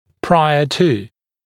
[‘praɪə tu][‘прайэ ту]до, перед, прежде чем